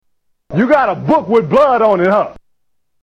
Televangelist